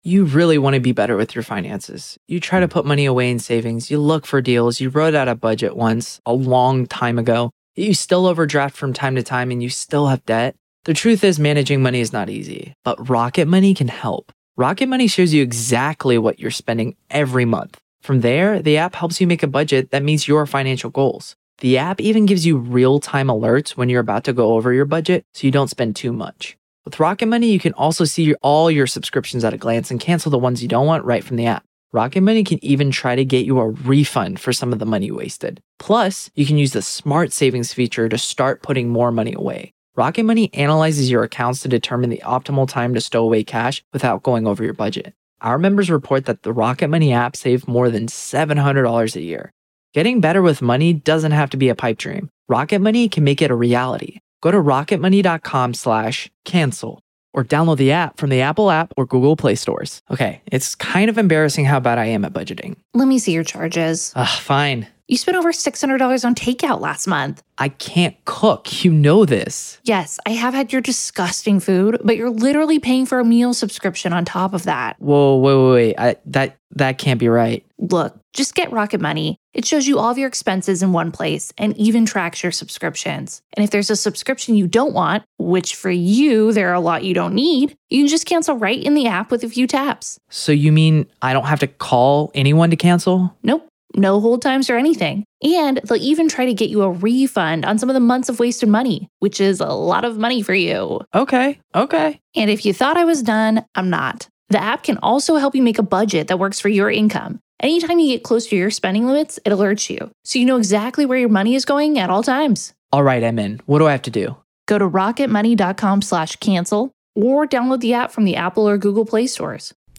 The latest Spanish news headlines in English: 7th April 2022 AM